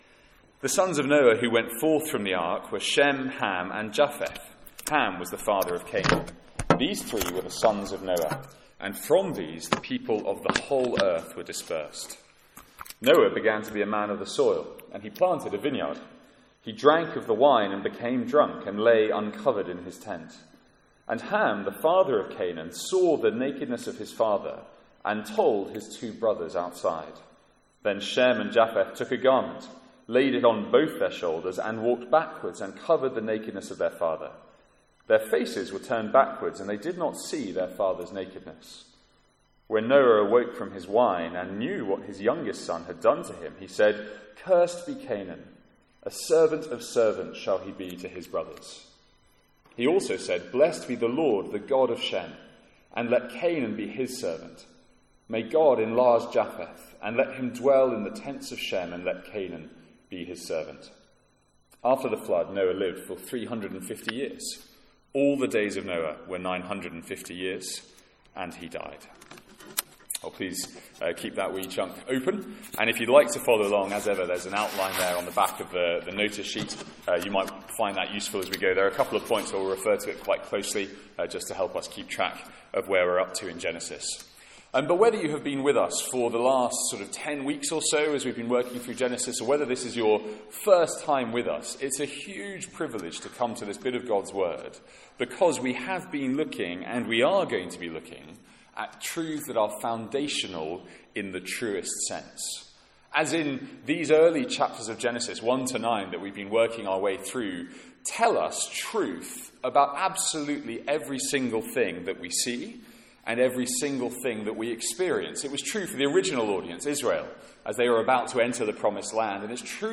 Sermons | St Andrews Free Church